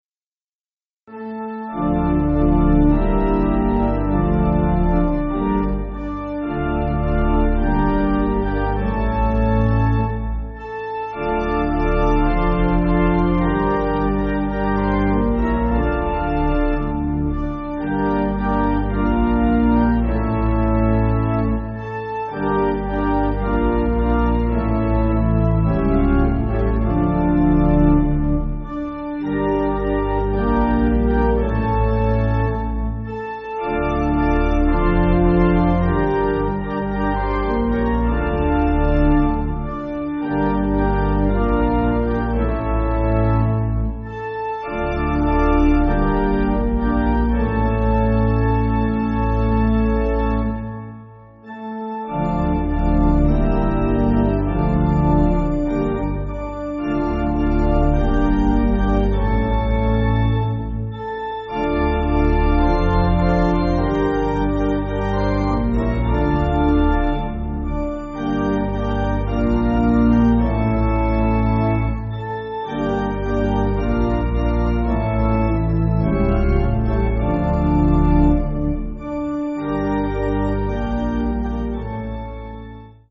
Organ
(CM)   3/Dm